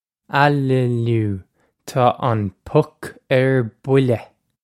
Pronunciation for how to say
Ah-lih-lew, taw on puck err bwilleh!
This is an approximate phonetic pronunciation of the phrase.